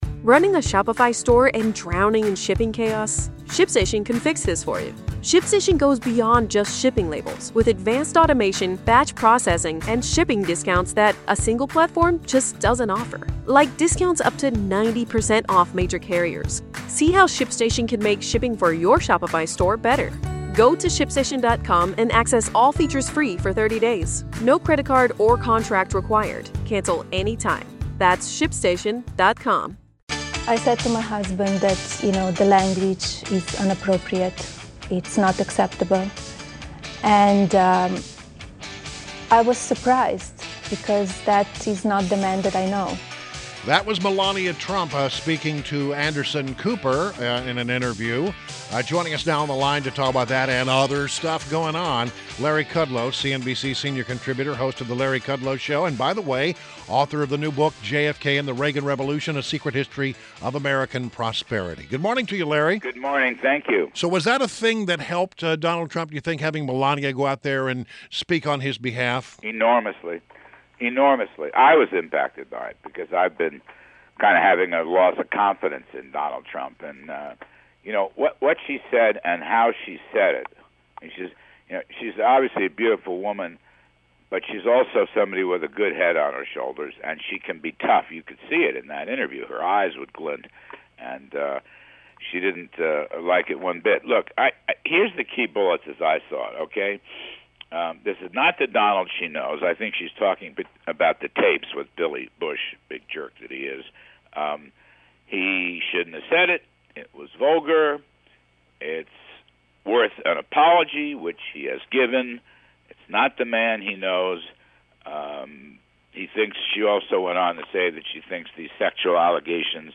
INTERVIEW -- LARRY KUDLOW - CNBC Senior Contributor and host of The Larry Kudlow Show on WMAL Saturdays at 7 pm